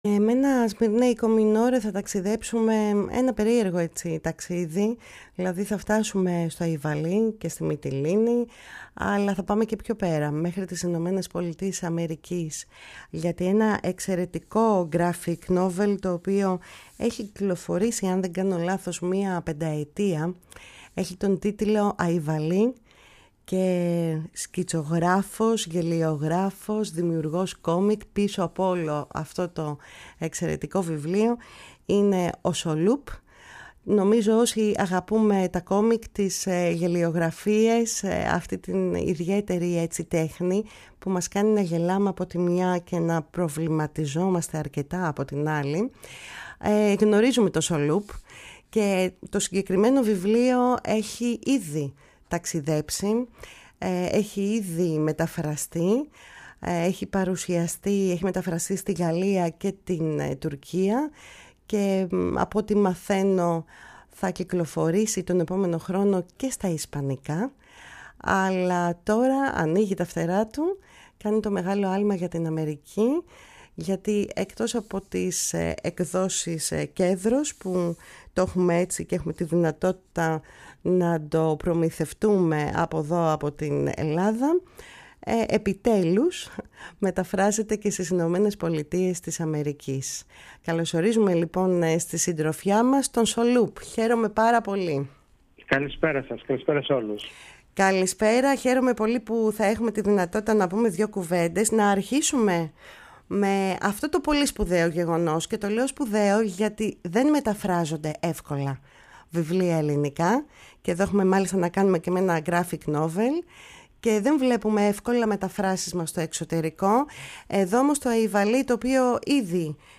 μίλησε για το Αϊβαλί, την πορεία του και τις παρουσιάσεις στις ΗΠΑ στη ΦΩΝΗ ΤΗΣ ΕΛΛΑΔΑΣ, και συγκεκριμένα στην εκπομπή “Κουβέντες μακρινές”